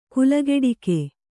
♪ kulageḍike